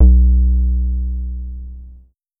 UMM BASS 4.wav